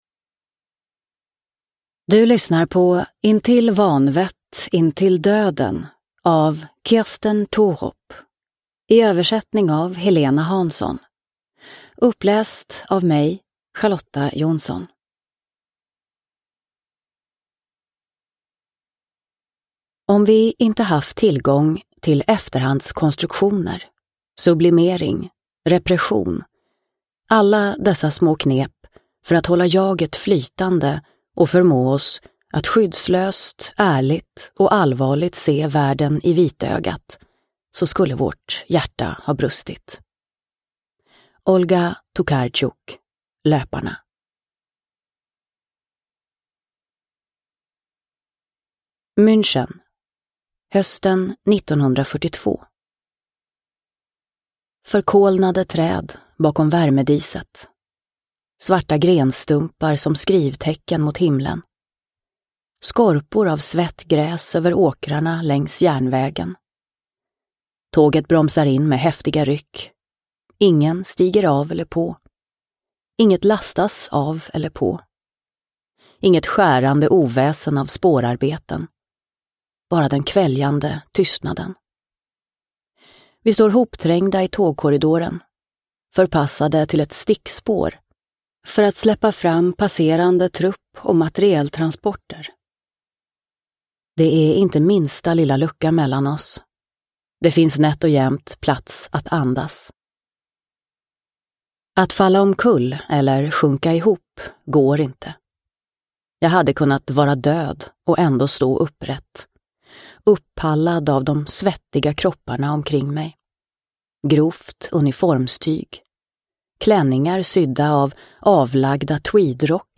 Intill vanvett, intill döden – Ljudbok – Laddas ner